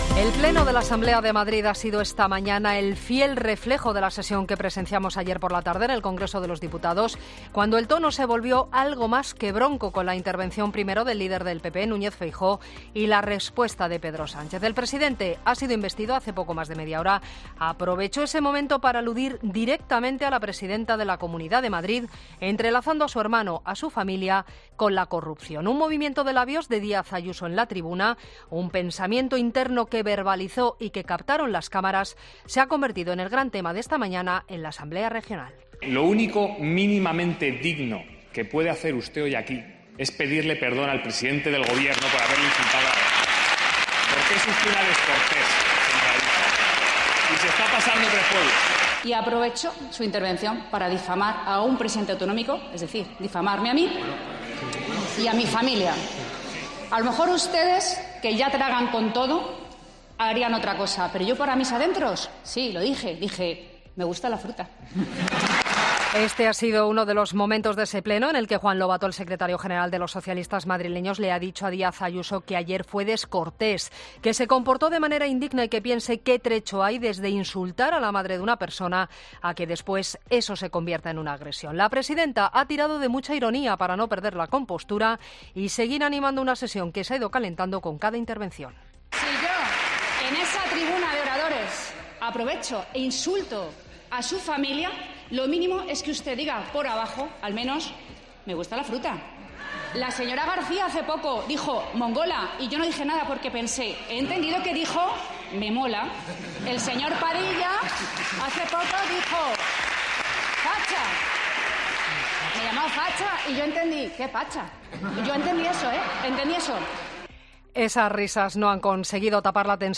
Sesión en la que el protagonismo ha recaído sobre el incidente de Ayuso ayer en el Congreso de los Diputados y la polémica sobre la amnistía.